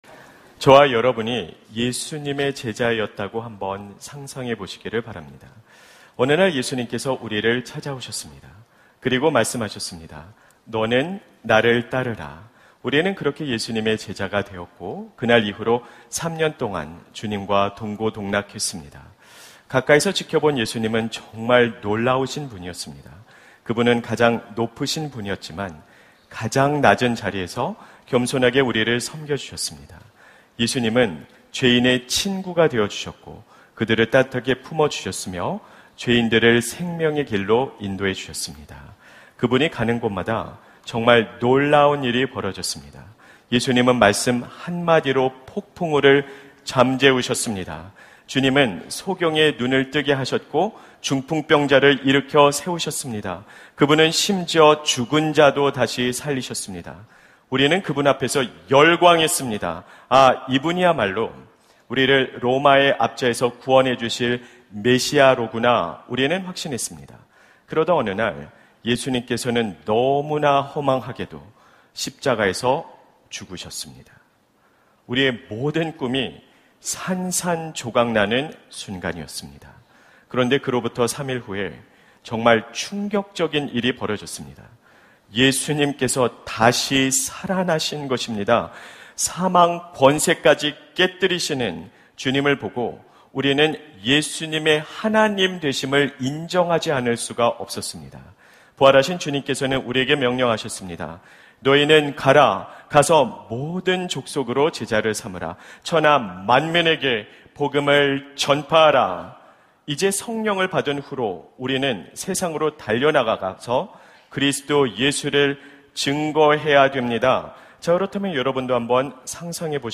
설교 : 주일예배 복음수업 - 복음수업 6 : 혹시 구멍 난 구명보트에 타고 계신가요? 설교본문 : 사도행전 16:29-32